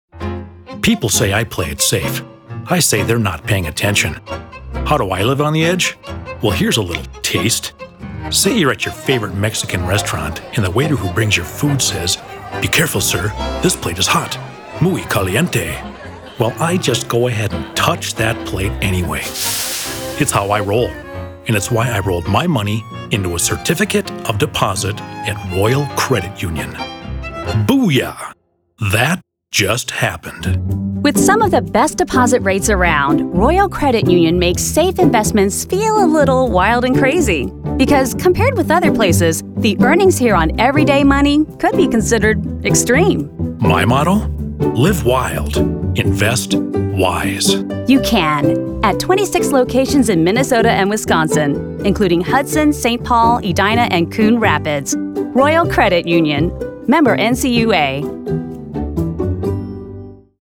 Commercial Radio: